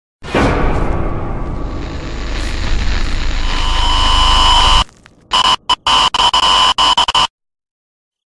creepy-music.mp3